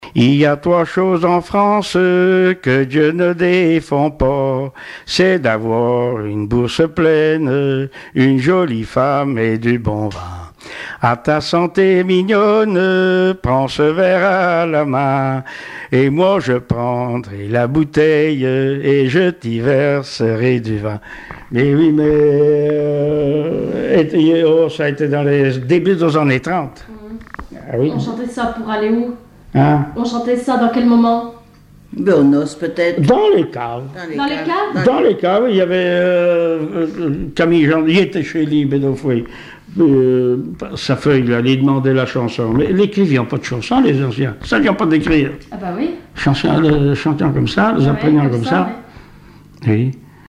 circonstance : bachique
Genre strophique
Témoignages et chansons traditionnelles et populaires
Pièce musicale inédite